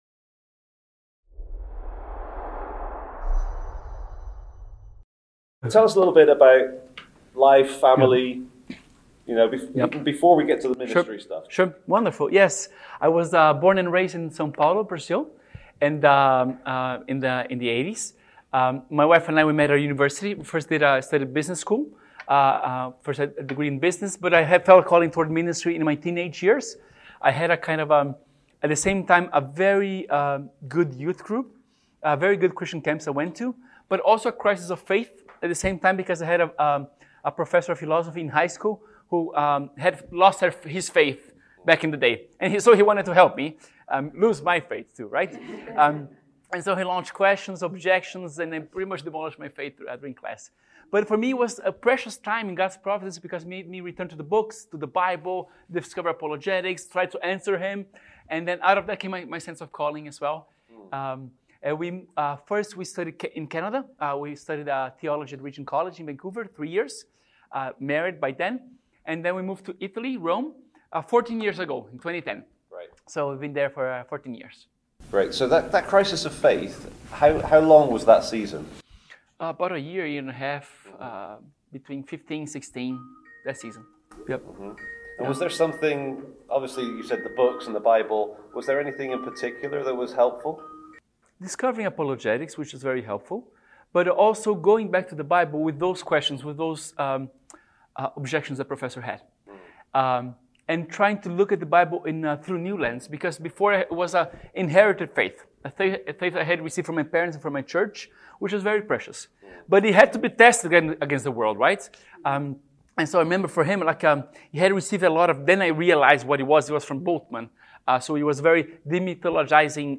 Preaching Revelation: Interview